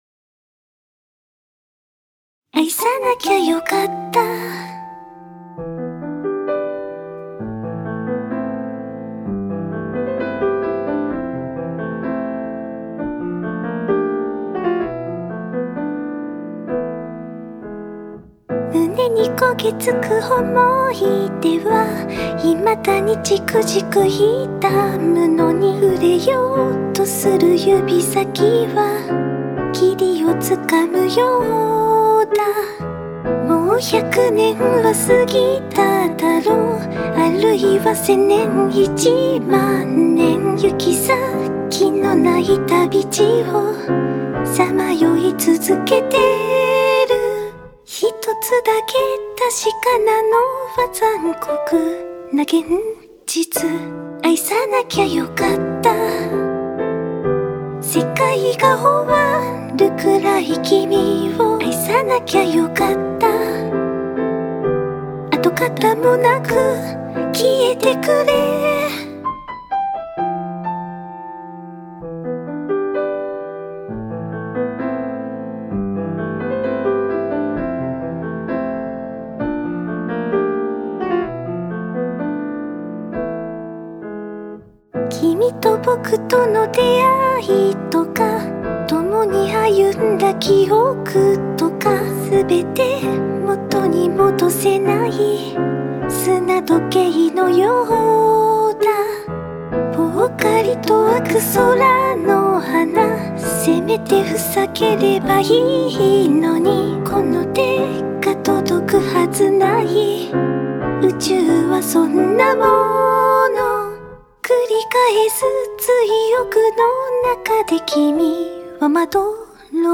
【Vocal / リマスター版2025】 mp3 DL ♪